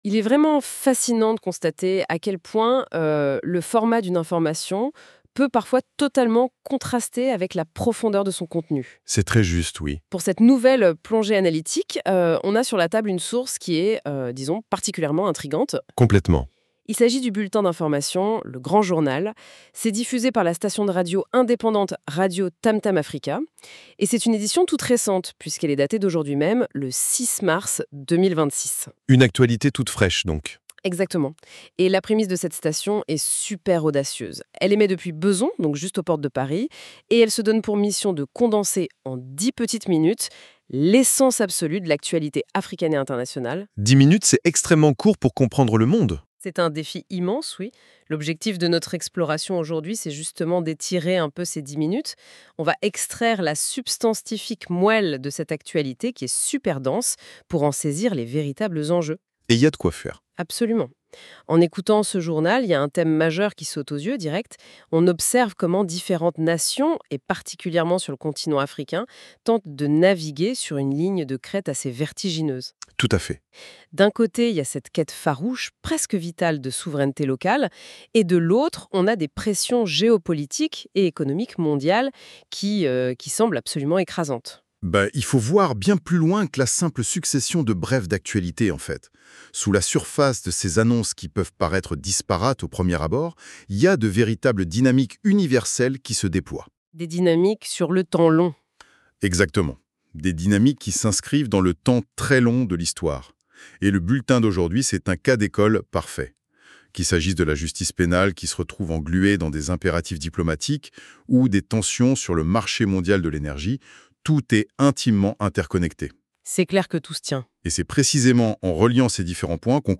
Radio TAMTAM AFRICA LE GRAND JOURNAL - RADIOTAMTAM AFRICA BREAKING NEWS AFRIQUE 06 mars 2026
Depuis Bezons, aux portes de Paris,vous écoutez RADIOTAMTAM AFRICA.